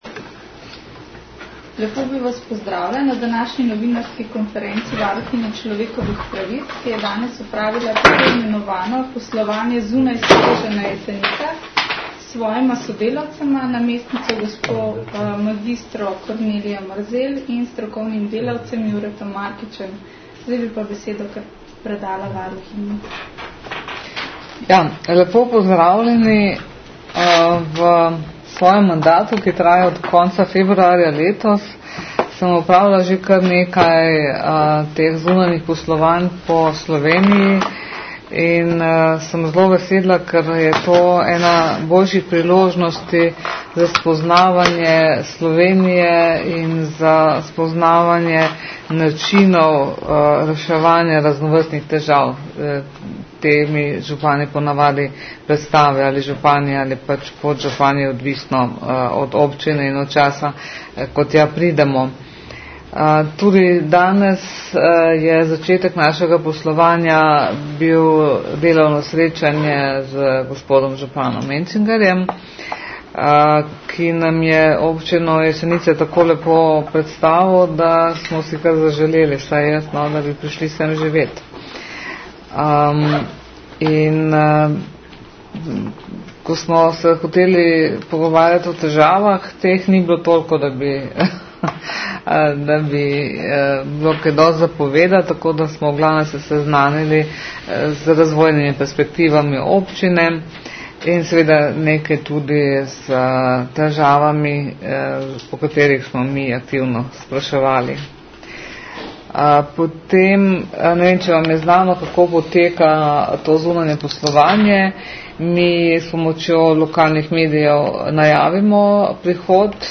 Po zaključku poslovanja je varuhinja svoje ugovotive predstavila na krajši novinarski konferenci.
Zvočni posnetek novinarske konference najdete tukaj.